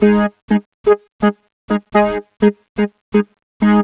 cch_synth_loop_jacker_125_G#m.wav